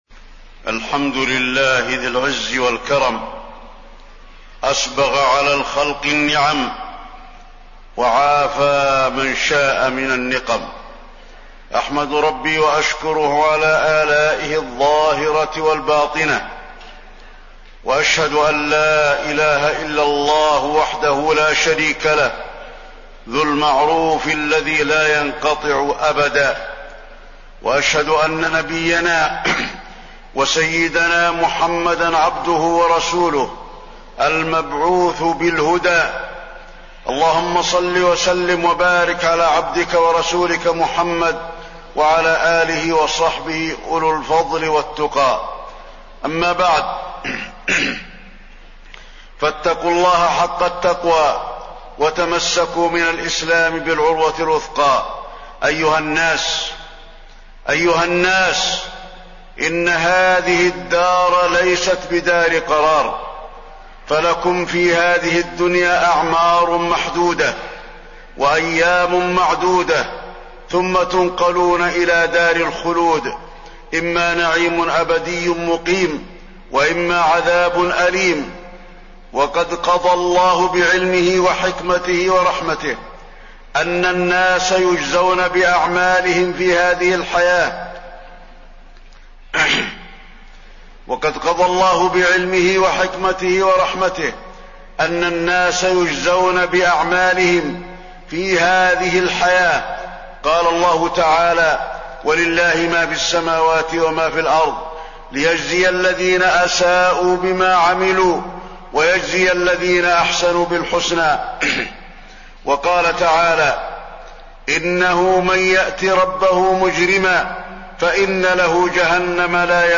تاريخ النشر ١٩ رمضان ١٤٣٢ هـ المكان: المسجد النبوي الشيخ: فضيلة الشيخ د. علي بن عبدالرحمن الحذيفي فضيلة الشيخ د. علي بن عبدالرحمن الحذيفي أسرار العبودية في رمضان